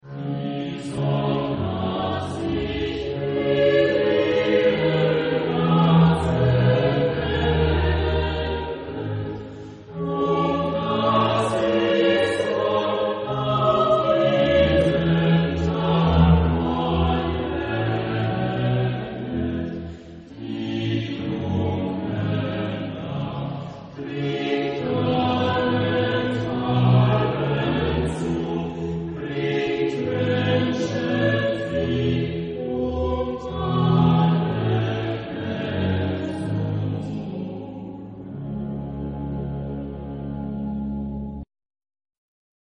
Genre-Style-Forme : Sacré ; Baroque
Type de choeur : SATB  (4 voix mixtes )